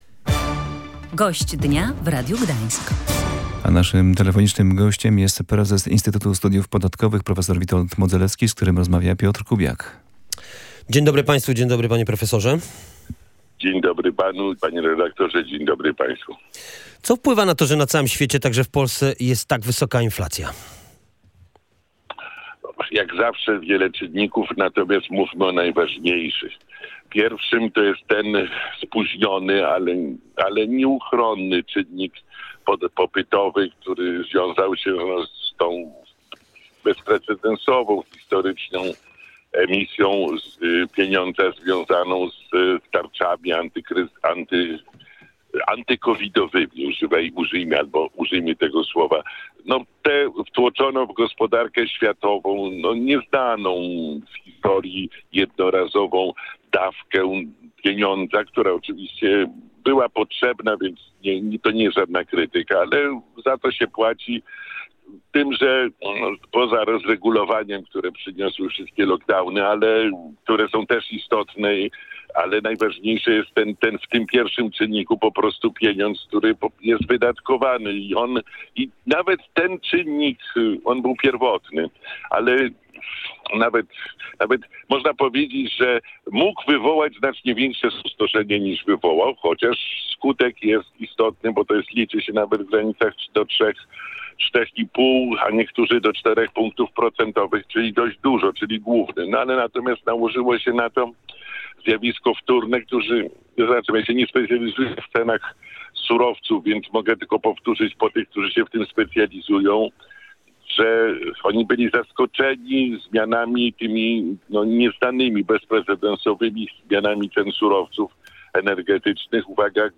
Wywołała ją konieczność wprowadzenia dodatkowej pomocy finansowej związanej z pandemią – mówił w Radiu Gdańsk prezes Instytutu Studiów Podatkowych, profesor Witold Modzelewski.